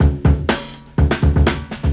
• 124 Bpm Drum Groove D Key.wav
Free breakbeat sample - kick tuned to the D note. Loudest frequency: 1124Hz
124-bpm-drum-groove-d-key-7fo.wav